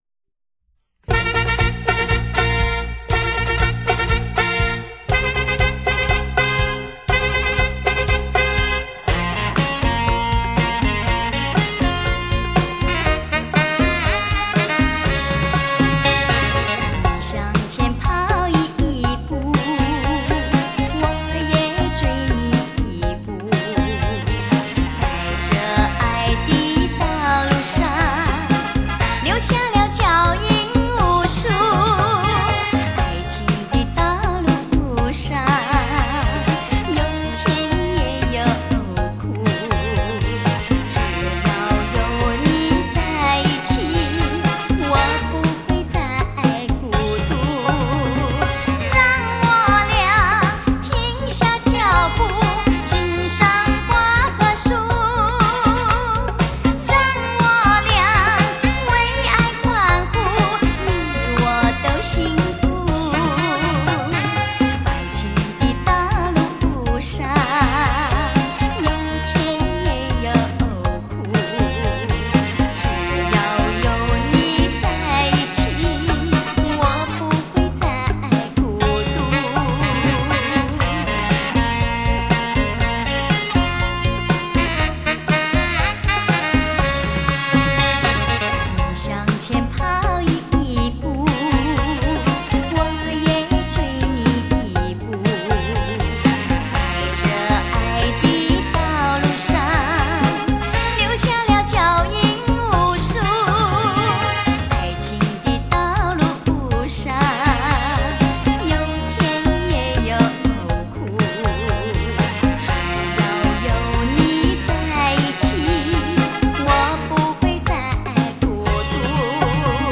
恰恰 灵魂舞 吉鲁巴 探戈 伦巴